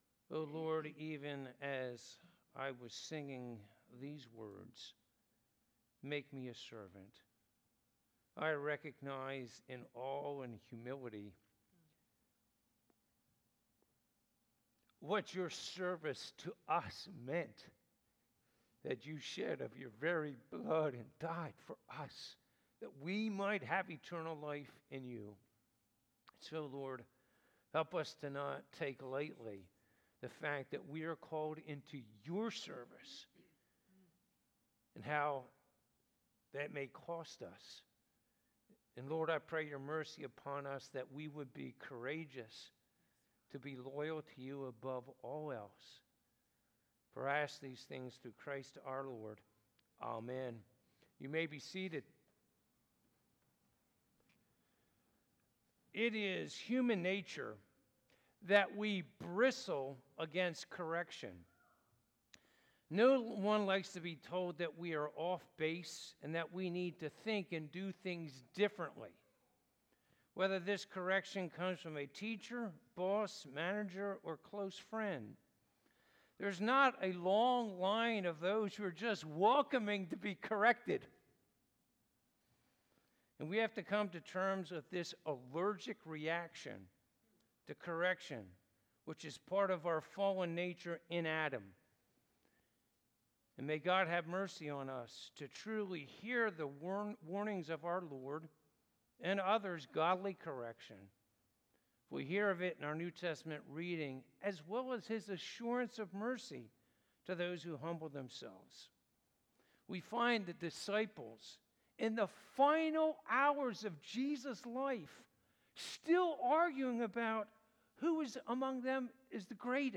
Passage: Luke 22:24-38 Service Type: Sunday Morning